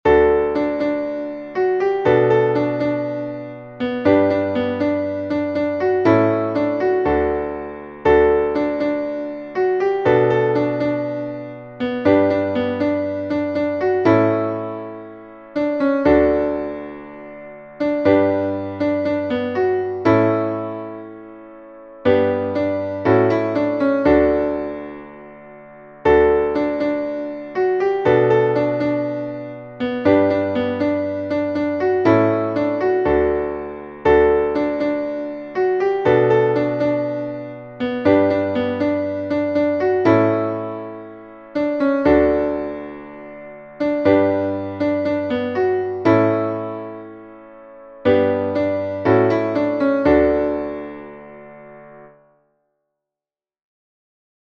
Kinderlied